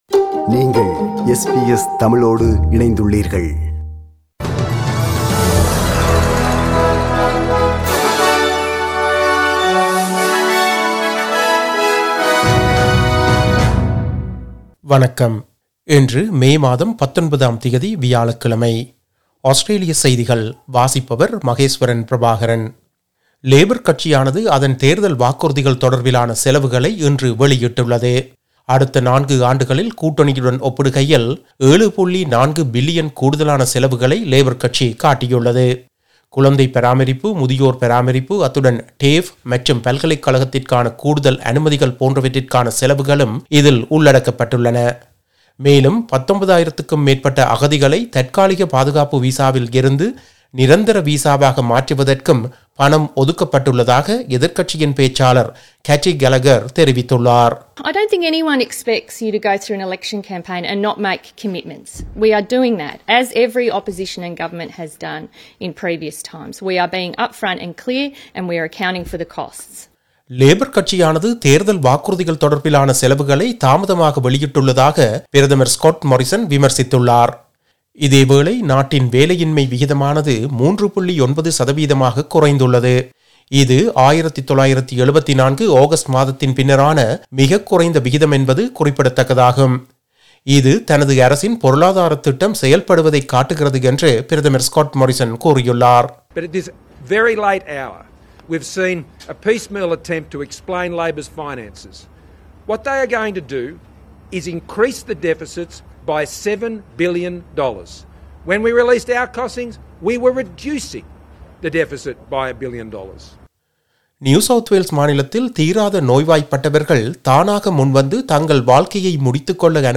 Australian news bulletin for Thursday 19 May 2022.